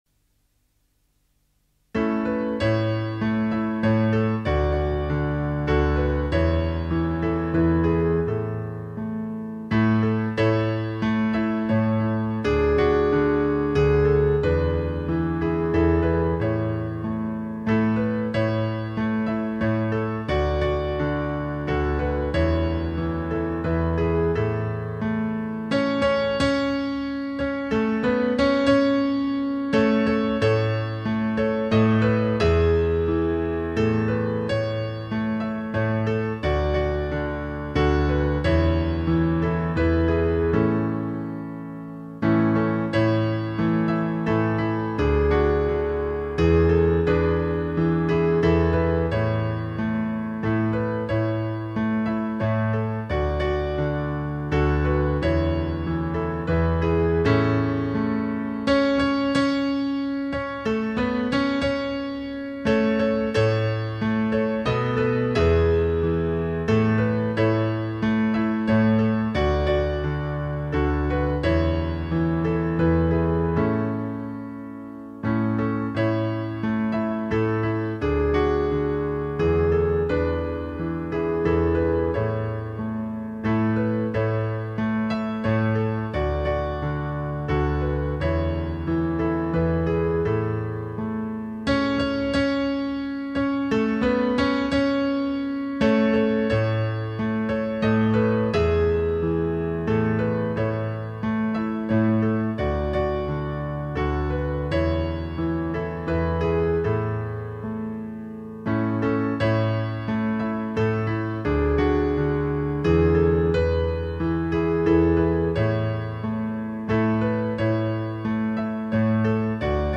Instrumental accompaniment